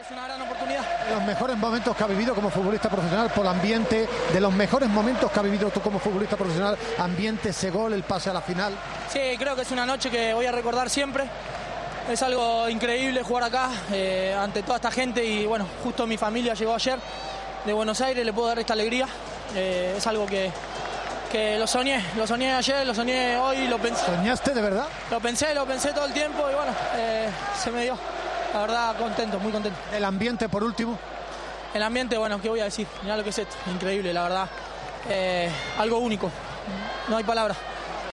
AUDIO: El jugador argentino habló en Movistar tras marcar el gol que le daba al Sevilla la clasificación para la final.